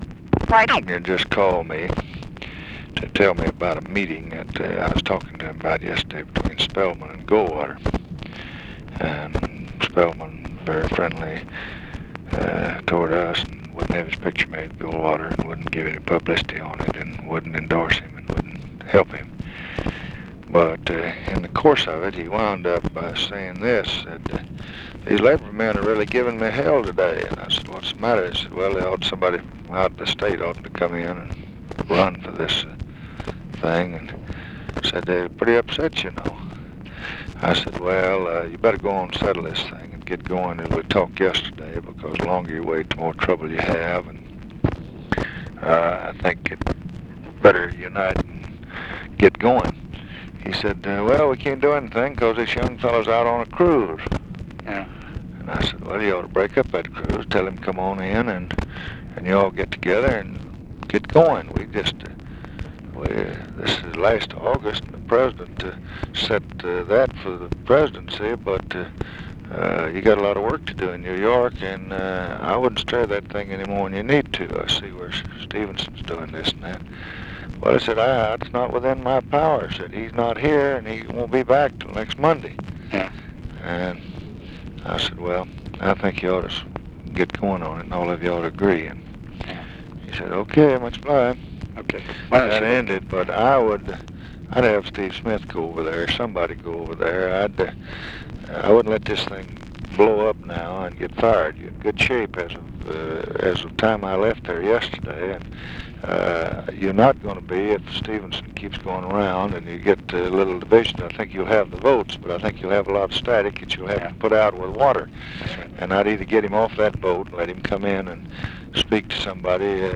Conversation with KEN O'DONNELL, August 13, 1964
Secret White House Tapes